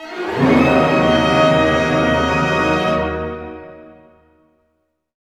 Index of /90_sSampleCDs/Roland LCDP08 Symphony Orchestra/ORC_Orch Gliss/ORC_Minor Gliss